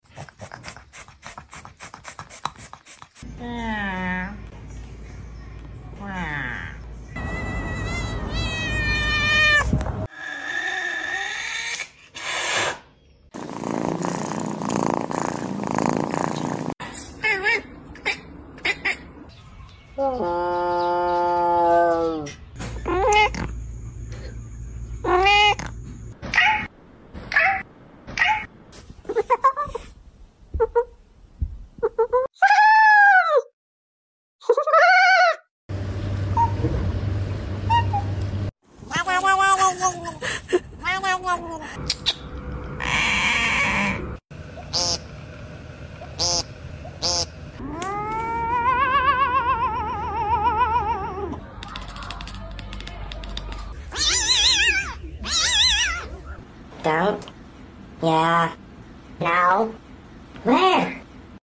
Cat can make all kinds sound effects free download
Cat can make all kinds of sounds.